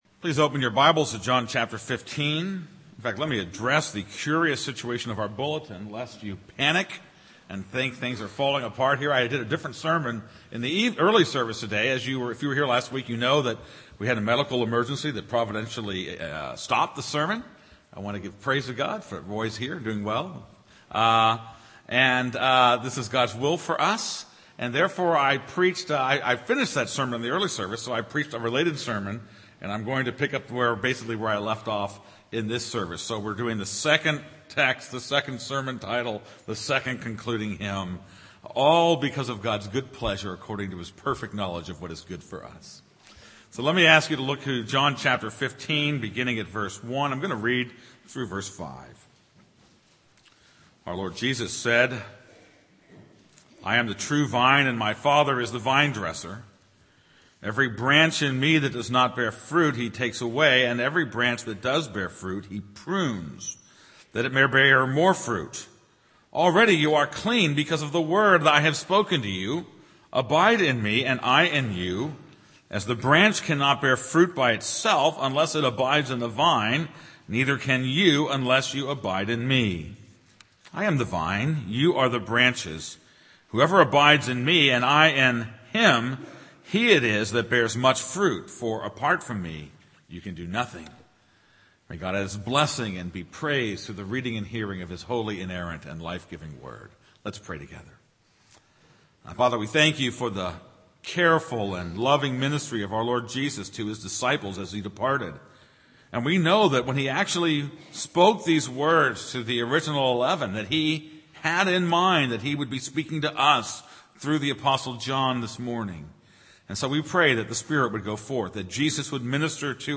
This is a sermon on John 15:1-5.